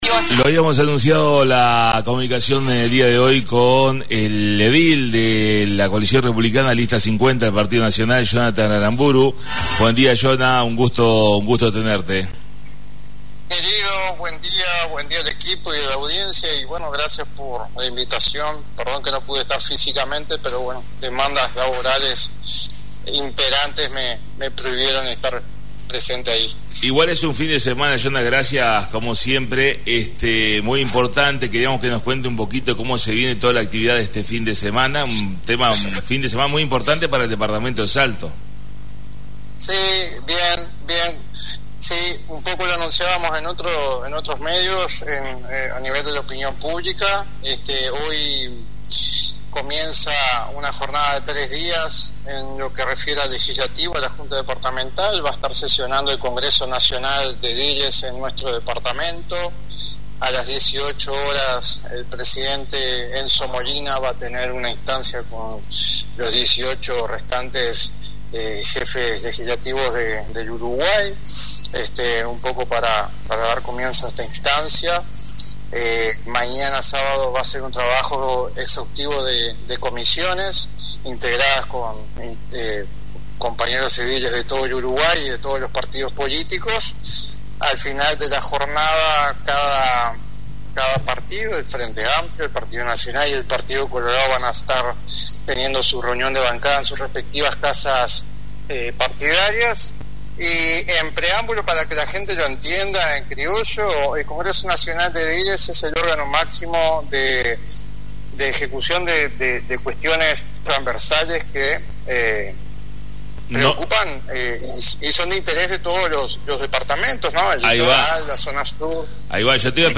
Comunicación en vivo con Jonnathan Aramburu Edil Partido Nacional (CORE)